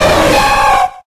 Audio / SE / Cries / SUICUNE.ogg